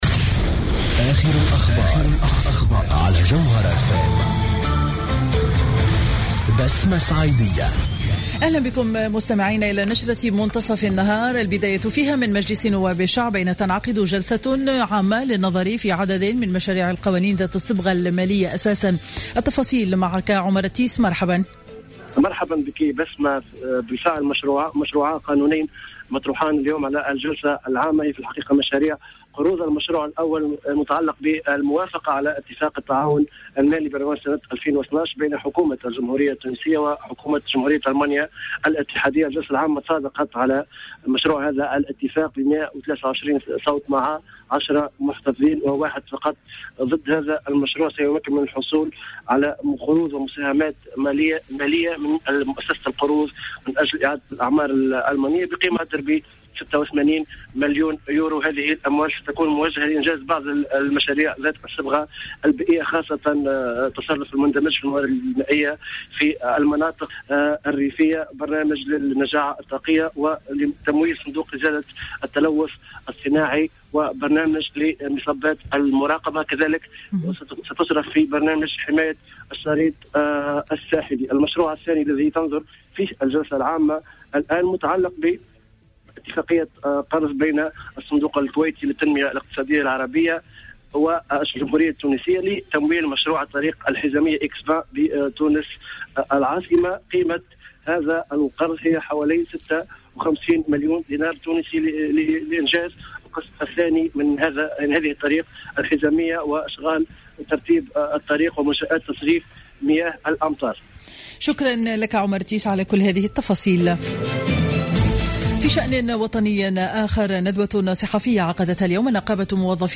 نشرة أخبار منتصف النهار ليوم الخميس 06 أوت 2015